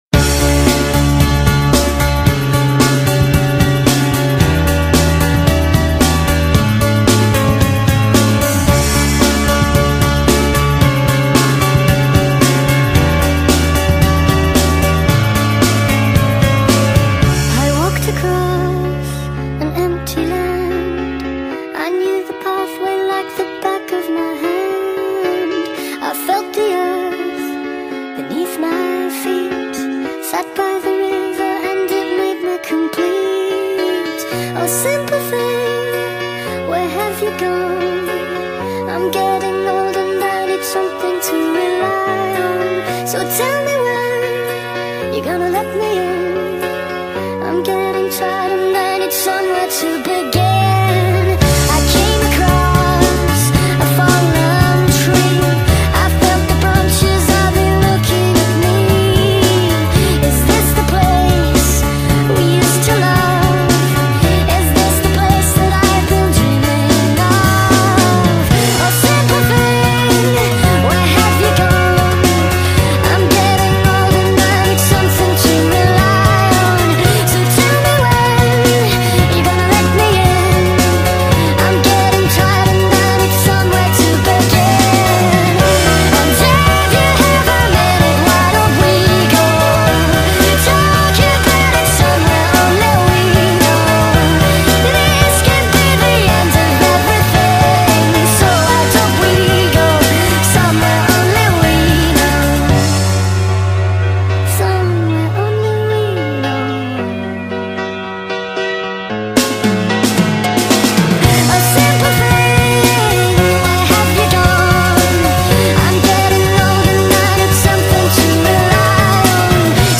با ریتمی سریع شده
غمگین